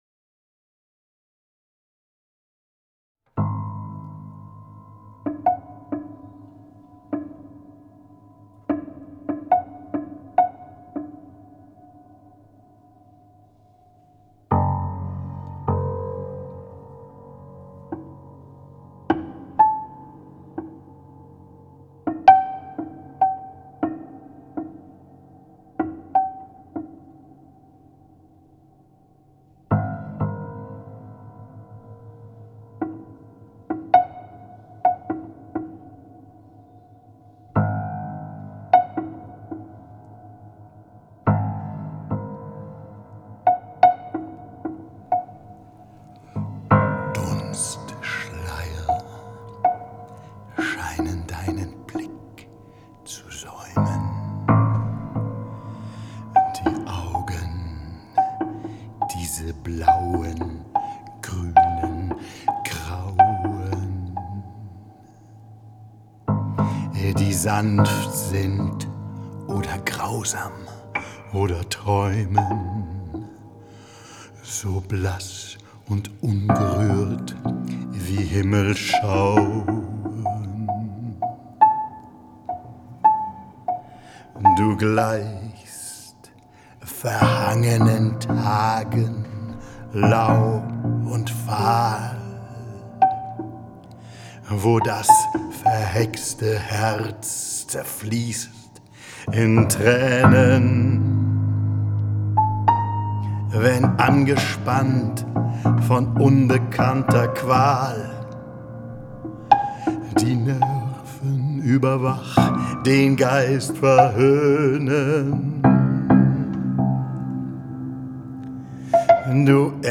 piano
chant